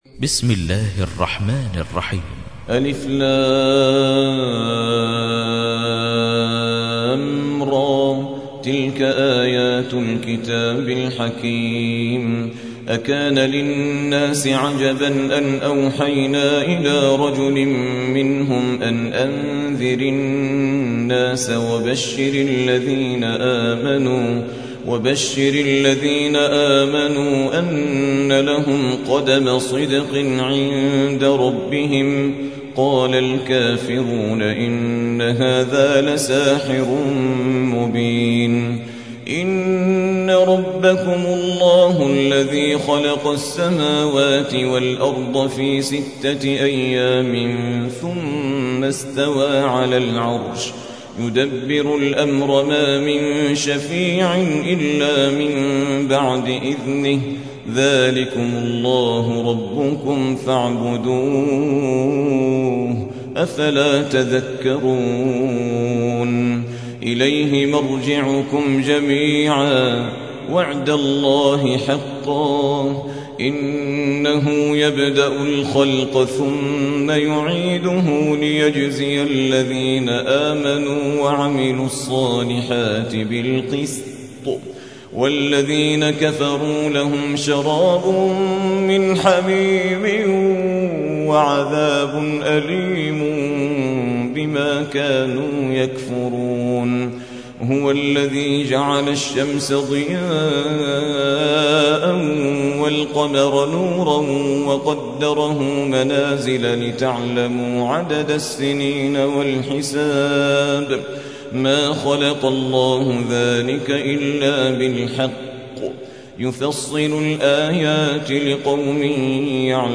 10. سورة يونس / القارئ